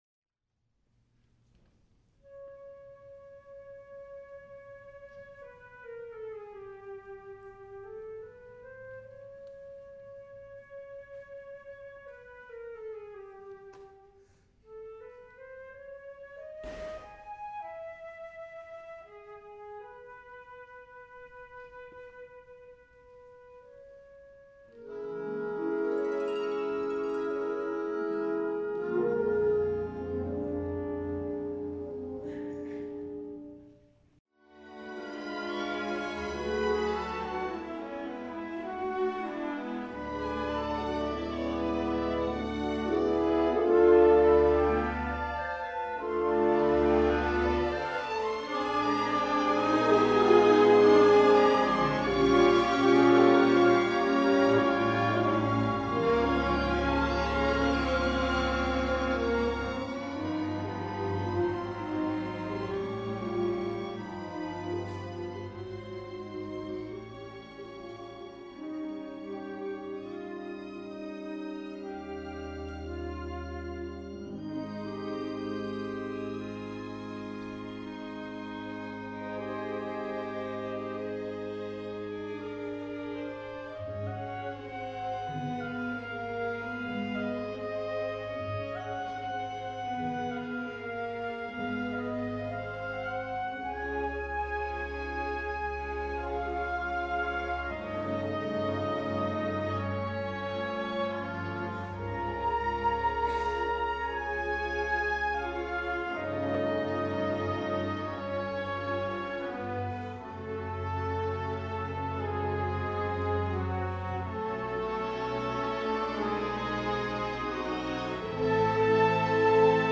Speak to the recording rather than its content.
Presbyterian Church of Los Gatos: 16575 Shannon Rd.